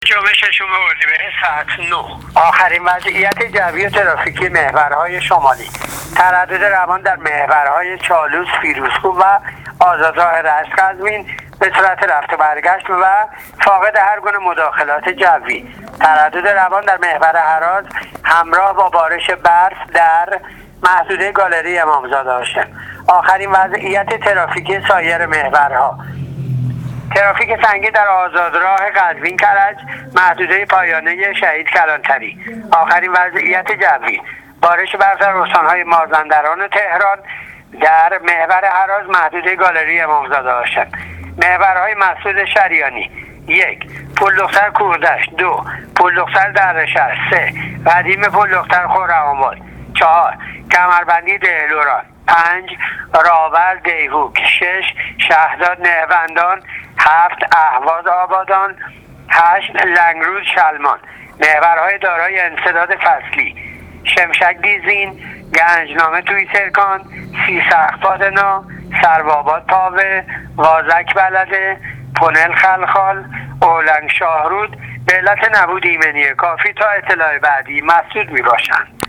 گزارش رادیو اینترنتی وزارت راه و شهرسازی از آخرین وضعیت‌ ترافیکی راه‌های کشور تا ساعت ۹ صبح ششم اردیبهشت ۹۸ / تردد روان در محورهای فیروزکوه، چالوس و قزوین-رشت